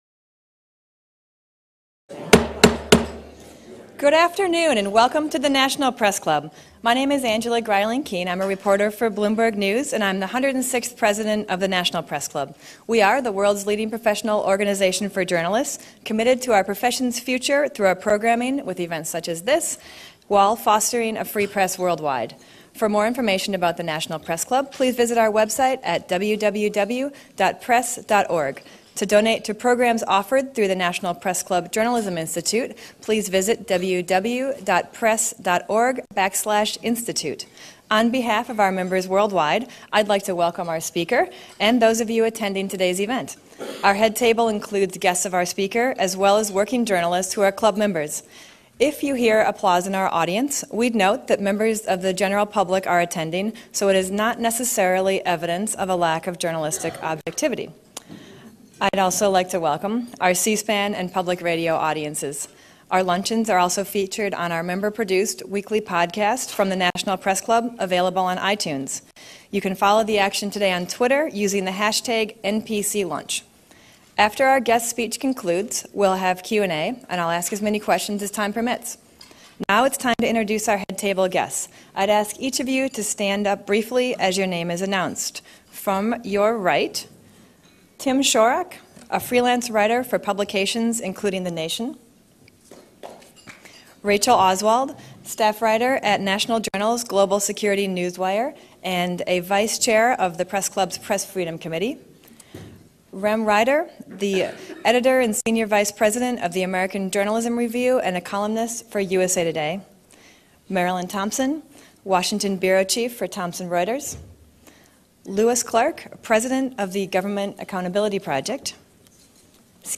4.1.3 NSA Whistleblower Thomas Drake speaks at National Press Club, March 15, 2013 (cache, audio only)
Thomas Drake, a former Senior Executive at NSA who was charged under the espionage act after he blew the whistle on waste and fraud and illegal activity at the intelligence agency, spoke at a March 15, 2013 National Press Club luncheon. Drake's event was part of the club's celebration of Sunshine Week, a national initiative to underscore the importance of open government and freedom of information.
Thomas_Drake_Ntl_Press_Club_2013_audio_only.mp3